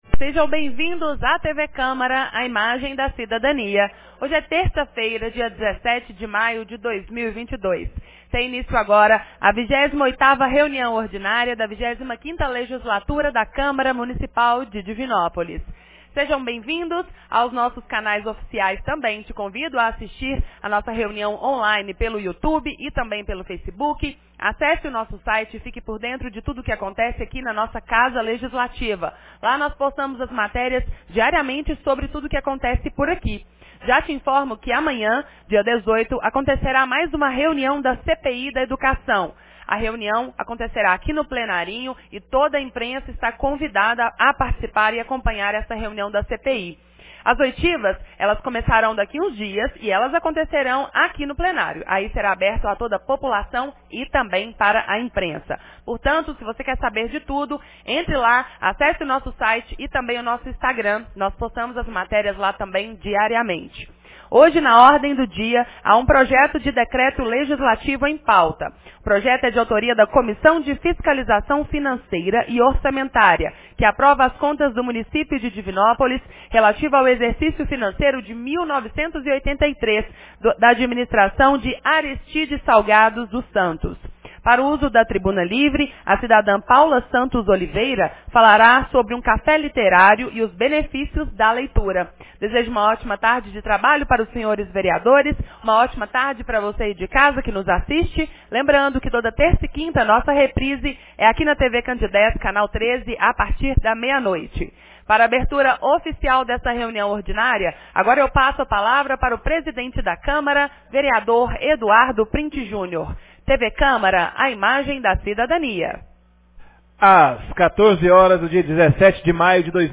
28ª Reunião Ordinária 17 de maio de 2022